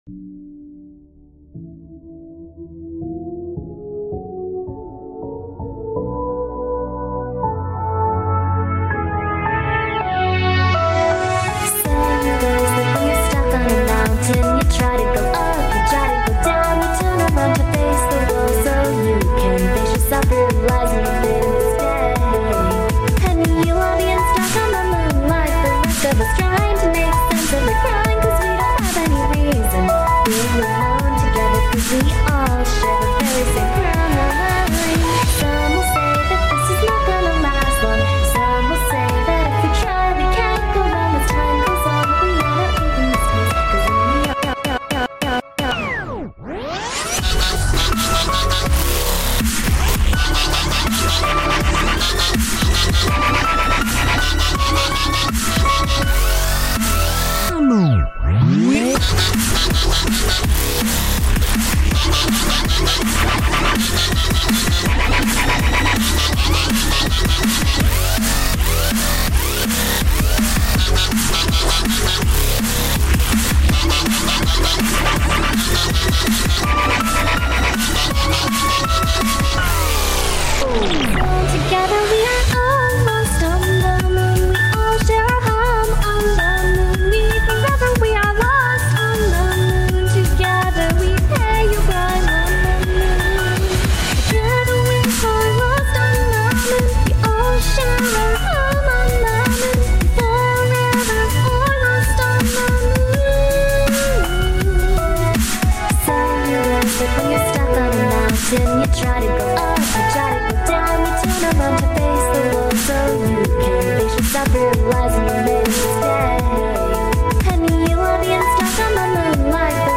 genre:dubstep